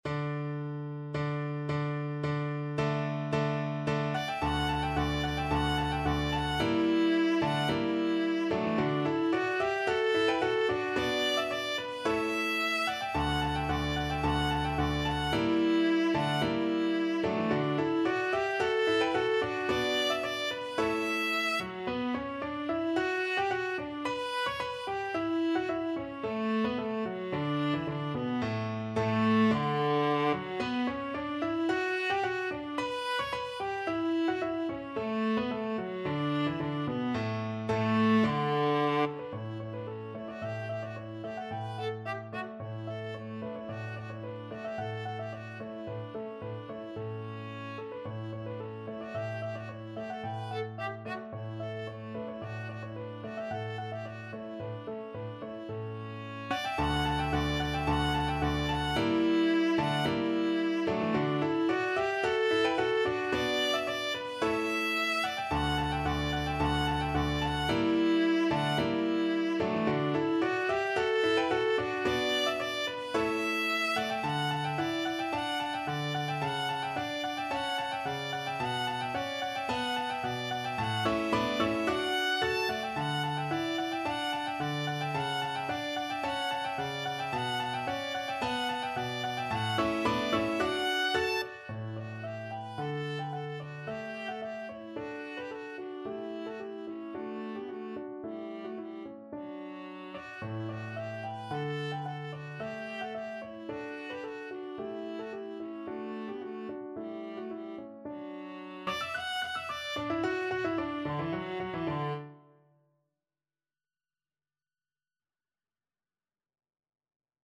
Viola
D major (Sounding Pitch) (View more D major Music for Viola )
= 110 Allegro di molto (View more music marked Allegro)
Classical (View more Classical Viola Music)